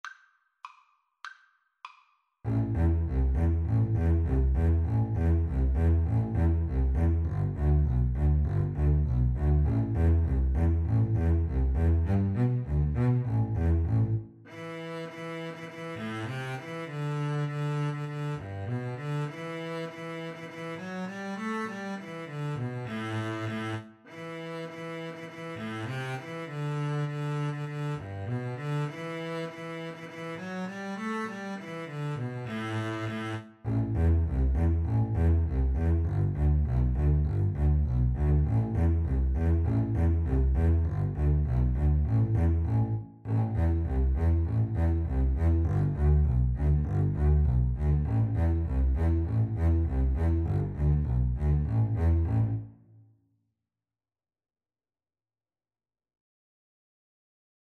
Double Bass Duet version
2/4 (View more 2/4 Music)